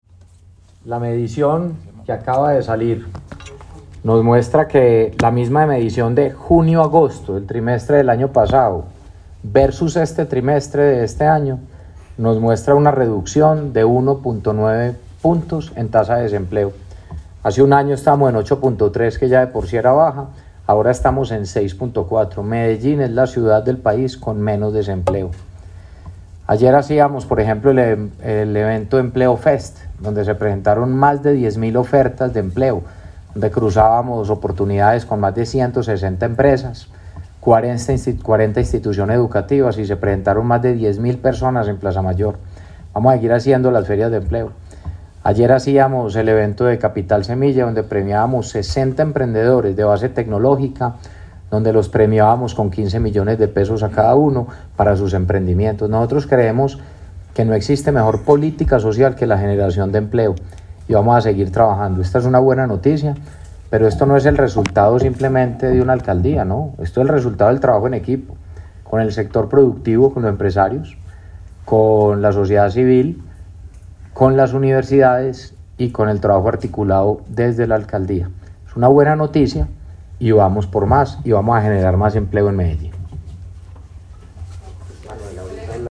Declaraciones-alcalde-de-Medellin-Federico-Gutierrez-6.mp3